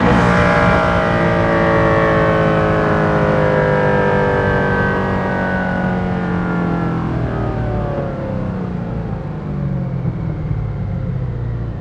rr3-assets/files/.depot/audio/Vehicles/ttv8_03/ttv8_03_decel.wav
ttv8_03_decel.wav